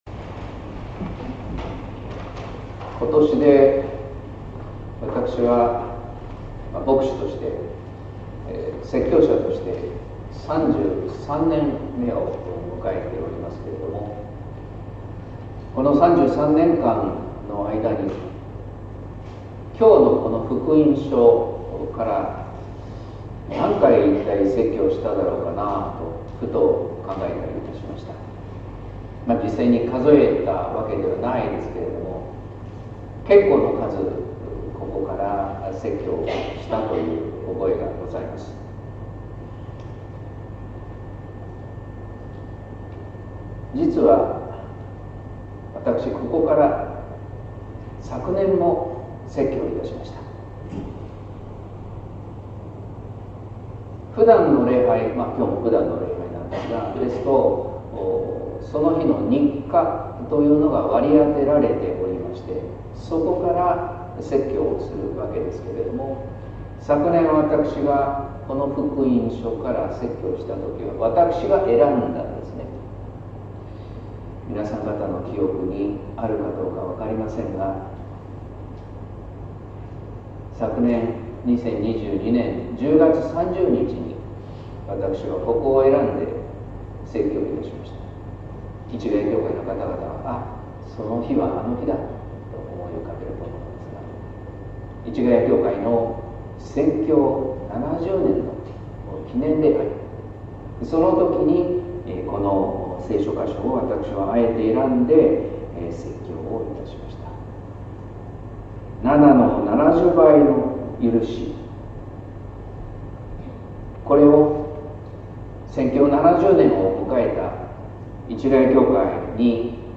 説教「天の国で借金帳消し」（音声版） | 日本福音ルーテル市ヶ谷教会
聖霊降臨後第16主日